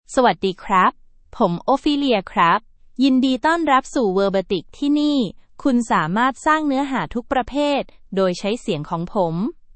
Ophelia — Female Thai AI voice
Ophelia is a female AI voice for Thai (Thailand).
Voice sample
Listen to Ophelia's female Thai voice.
Ophelia delivers clear pronunciation with authentic Thailand Thai intonation, making your content sound professionally produced.